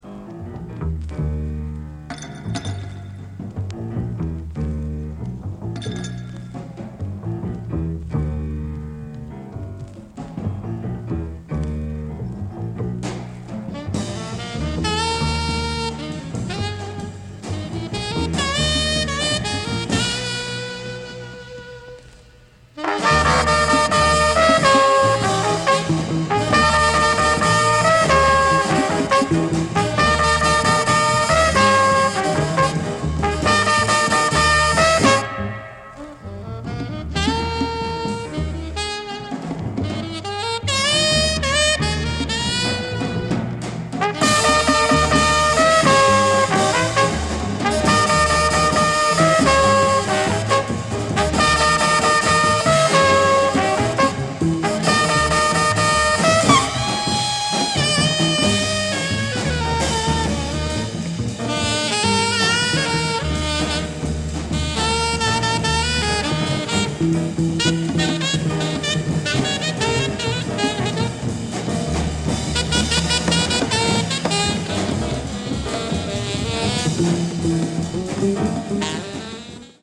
Free-Jazz holy grail.